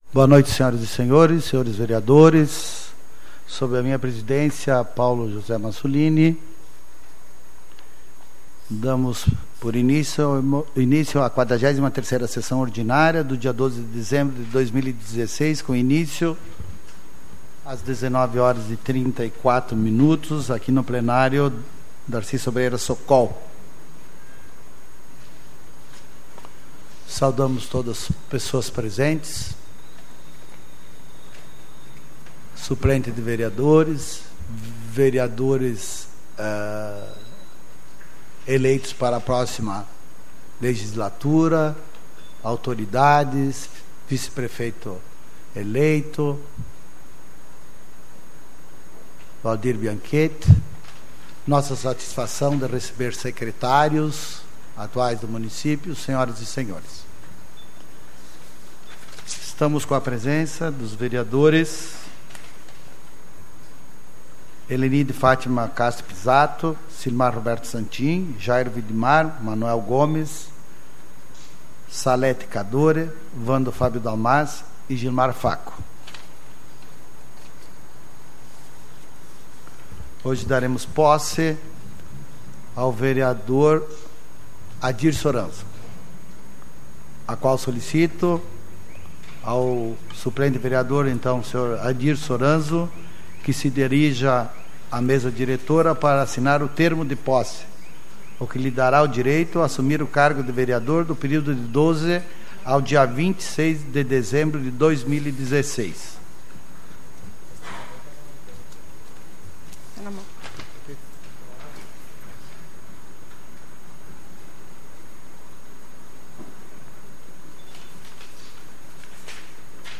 SAPL - Câmara de Vereadores de Serafina Corrêa - RS
Tipo de Sessão: Ordinária